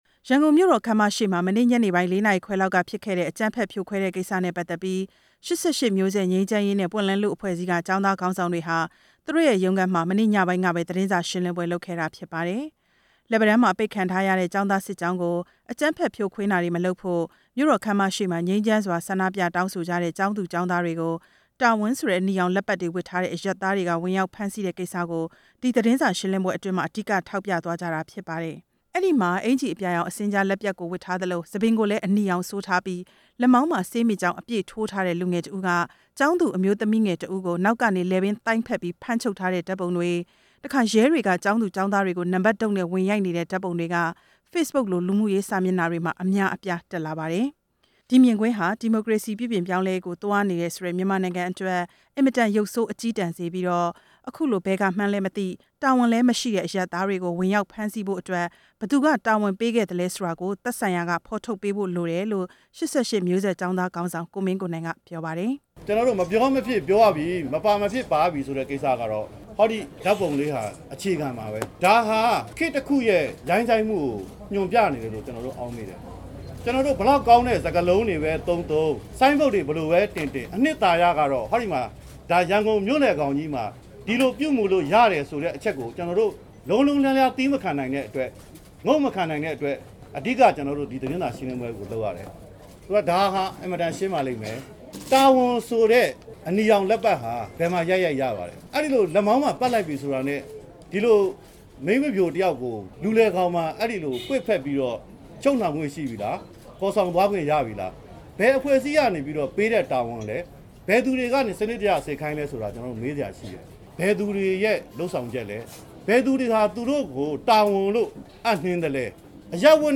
၈၈ သတင်းစာရှင်းလင်းပွဲ တင်ပြချက်